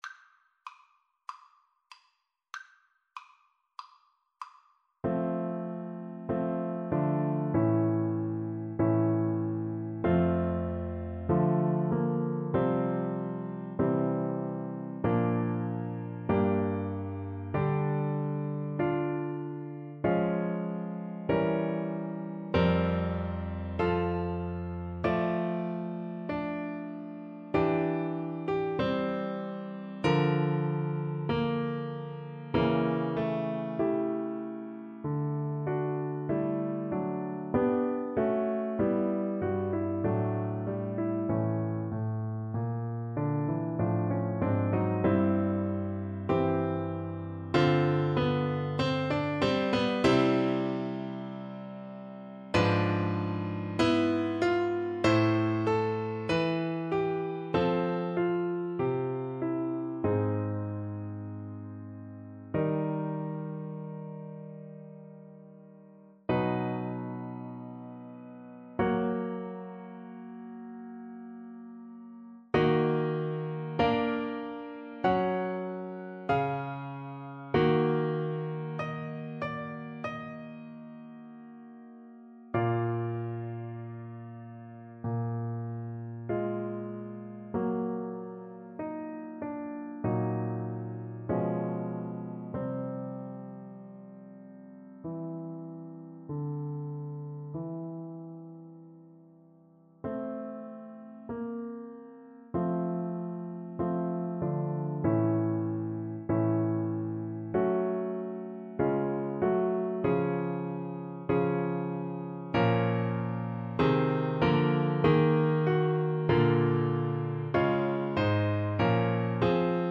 Play (or use space bar on your keyboard) Pause Music Playalong - Piano Accompaniment Playalong Band Accompaniment not yet available transpose reset tempo print settings full screen
F major (Sounding Pitch) G major (Clarinet in Bb) (View more F major Music for Clarinet )
~ = 96 Alla breve. Weihevoll.
Classical (View more Classical Clarinet Music)